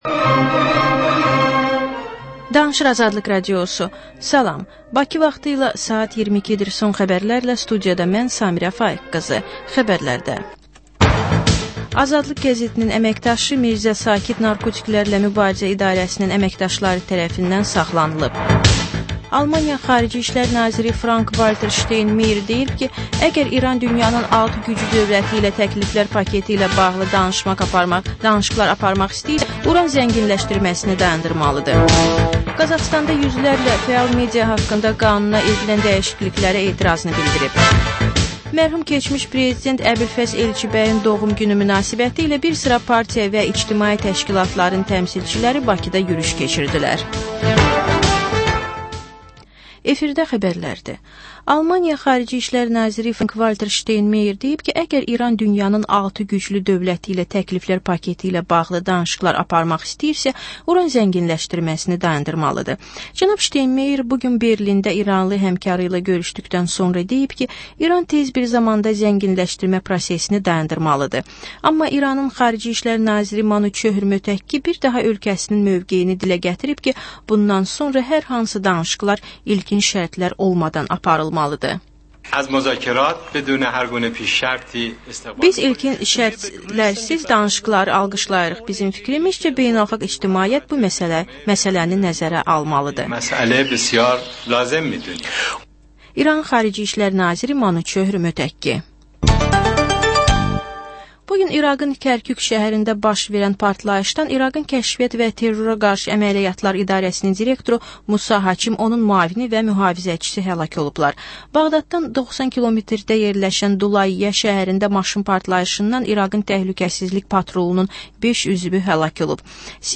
Xəbərlər, reportajlar, müsahibələr. Və: Qafqaz Qovşağı: Azərbaycan, Gürcüstan və Ermənistandan reportajlar.